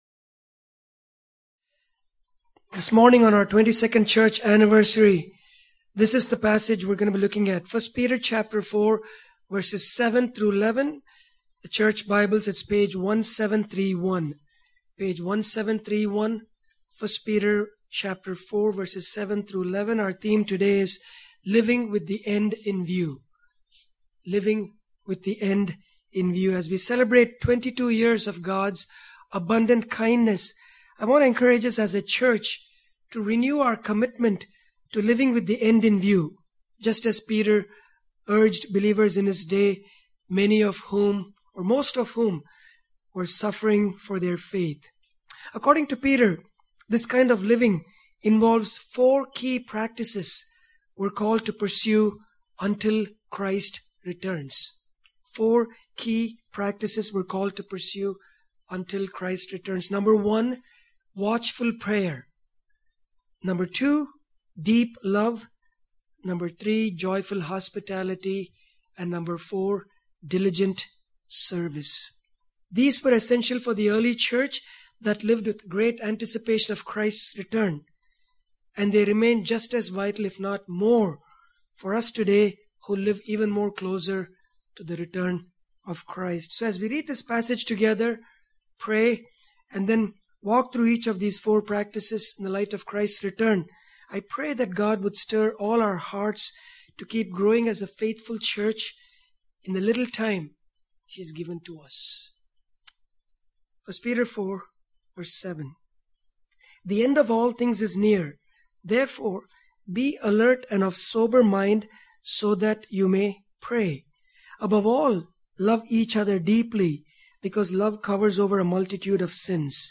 2025 Living With The End In View Preacher